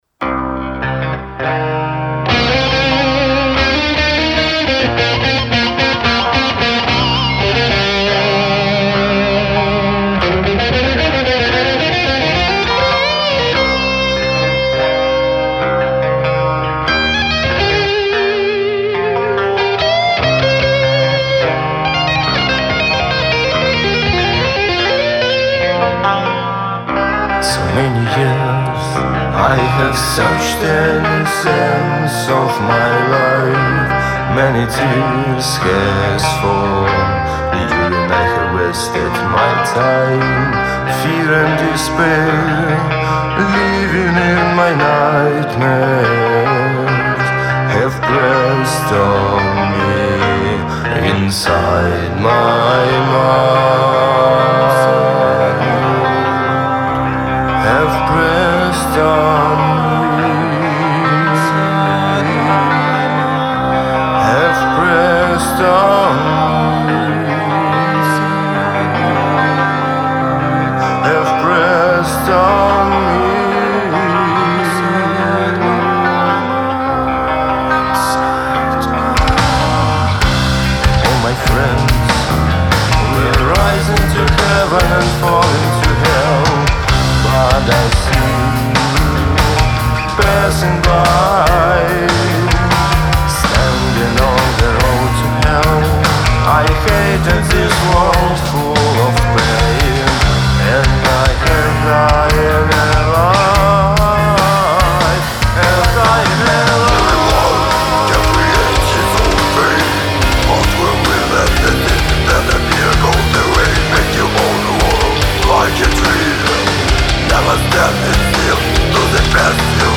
Студыя Rock-Center Studio
vocal
guitar
bass
keyboards
drums